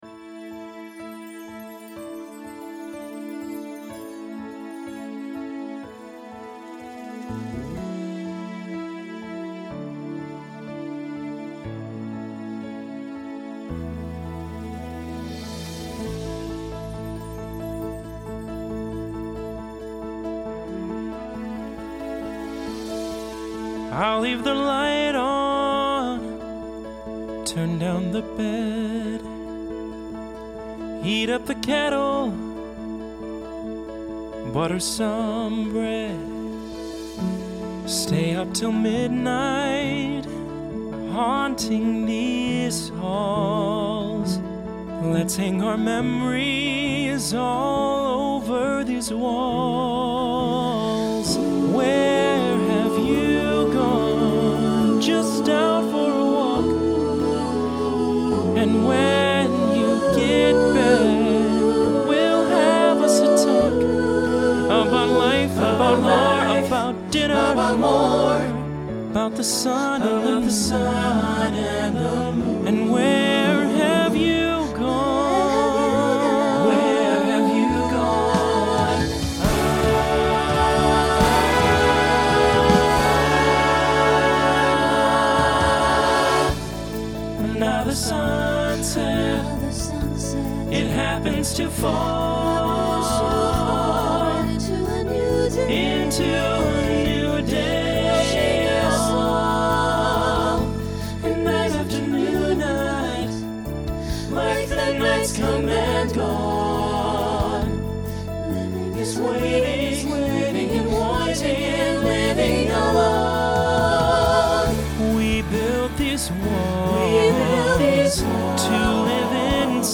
Genre Broadway/Film
Ballad , Solo Feature Voicing SATB